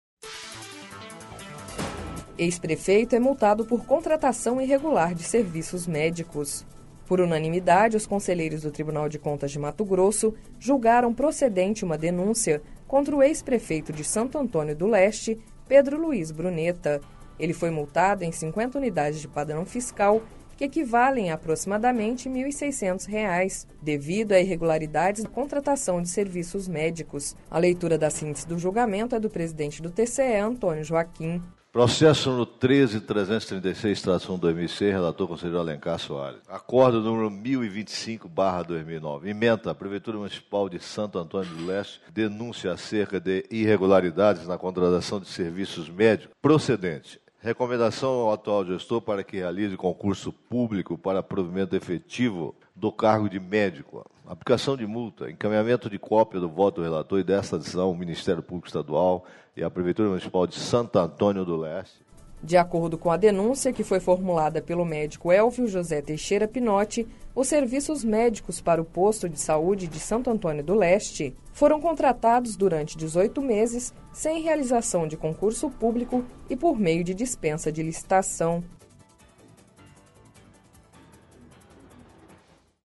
Sonora: Antonio Joaquim - conselheiro presidente do TCE-MT